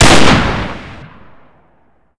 sol_reklam_link sag_reklam_link Warrock Oyun Dosyalar� Ana Sayfa > Sound > Weapons > AKS74U Dosya Ad� Boyutu Son D�zenleme ..
WR_fire.wav